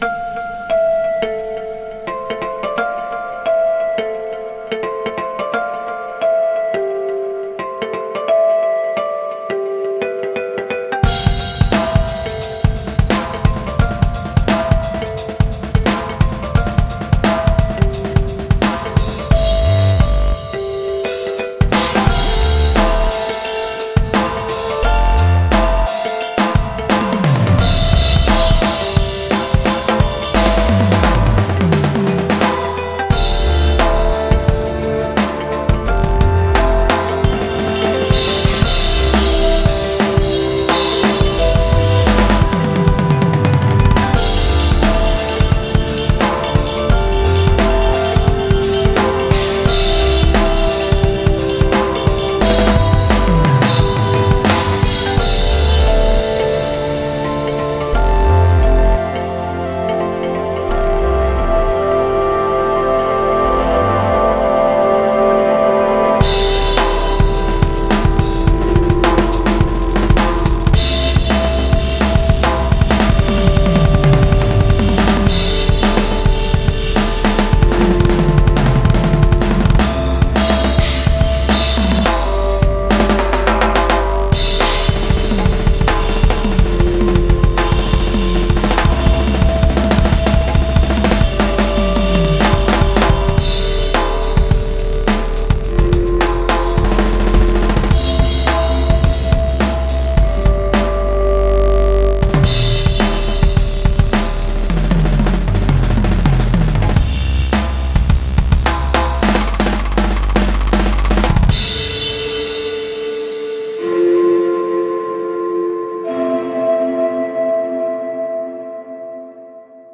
BPM174-212
Audio QualityMusic Cut